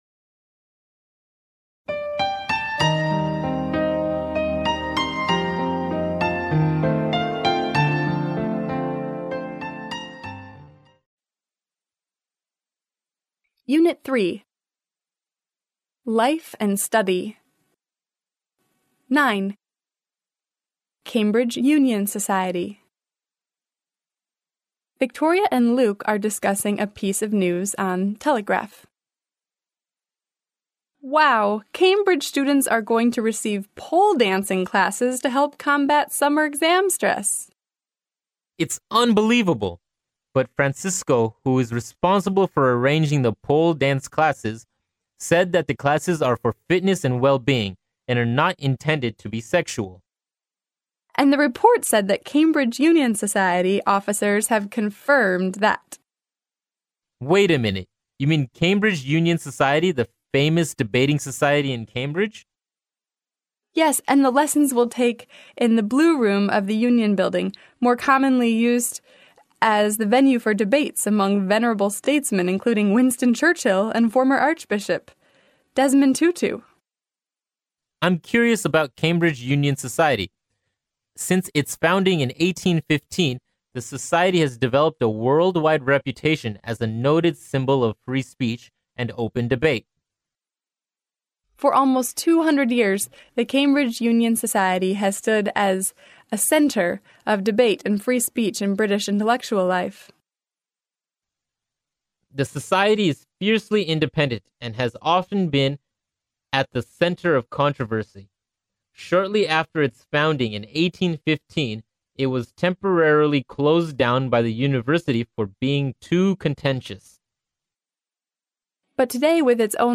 剑桥大学校园英语情景对话09：唇枪舌剑（mp3+中英）